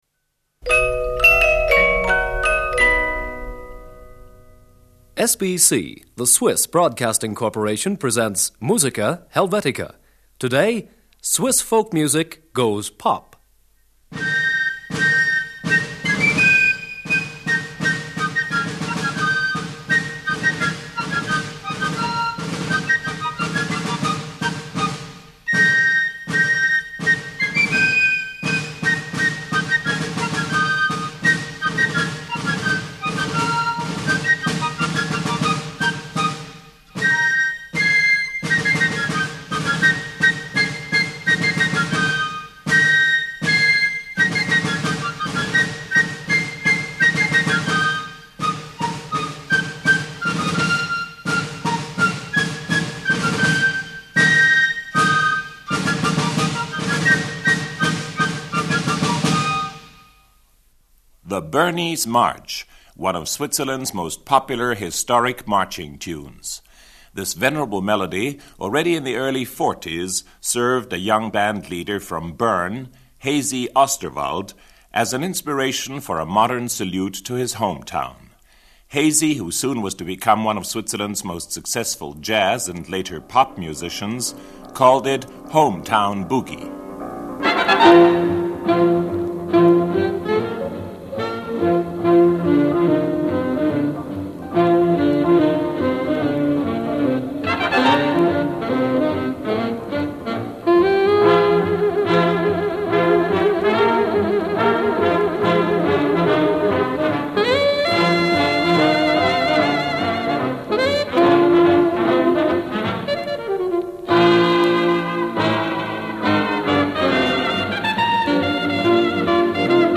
Film music (excerpt).